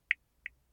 indicator.mp3